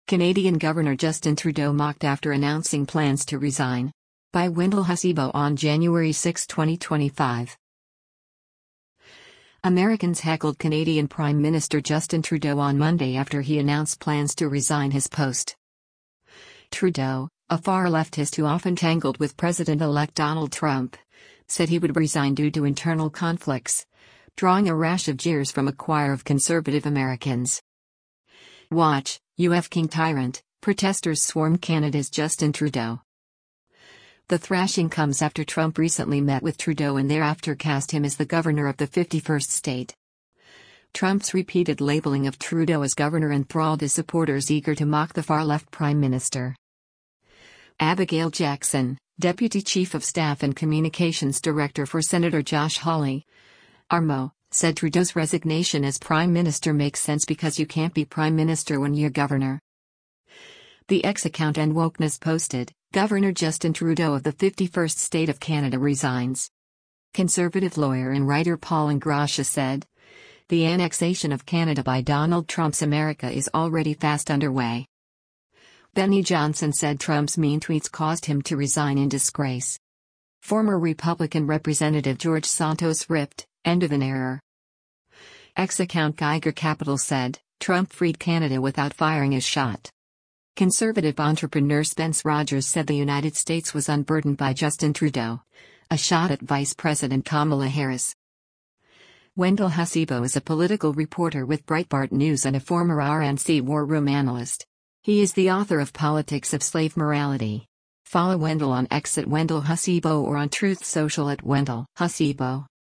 WATCH — “You F*cking Tyrant!”: Protesters SWARM Canada’s Justin Trudeau: